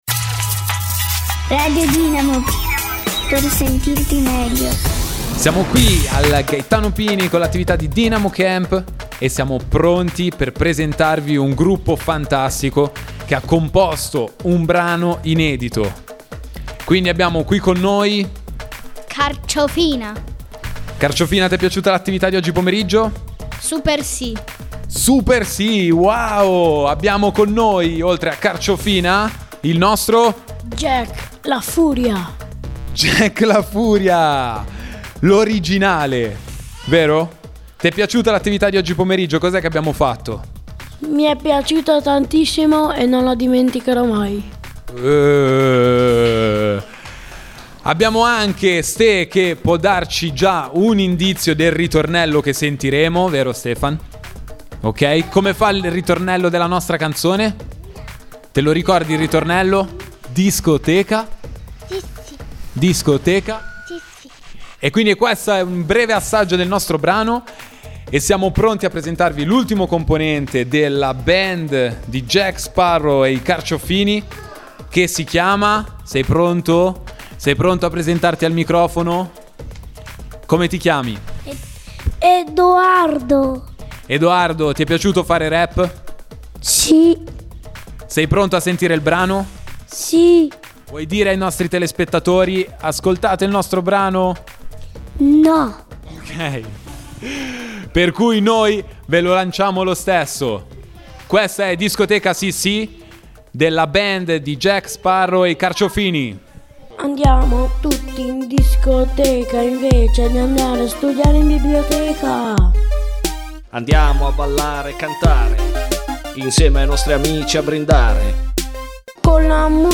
LA VOSTRA CANZONE RAP!!